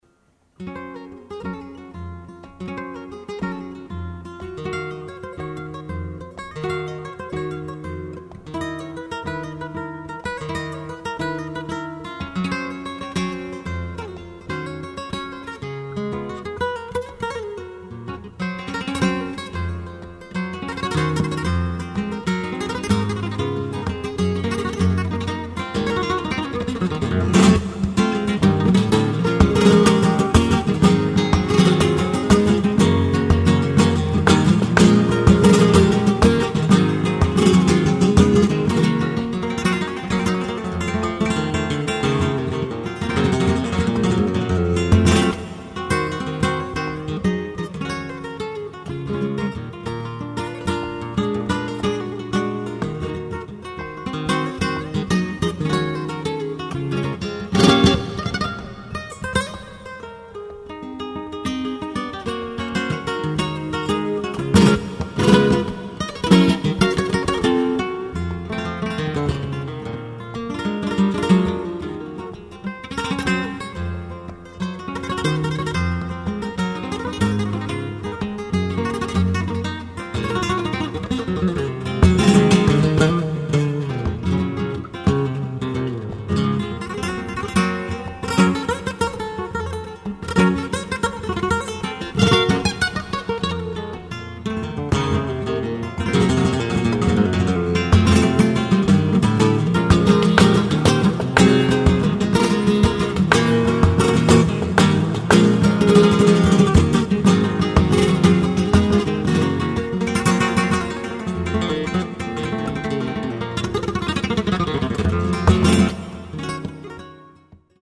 ライブ・アット・サンセバスチャン、スペイン 07/23/1987
※試聴用に実際より音質を落としています。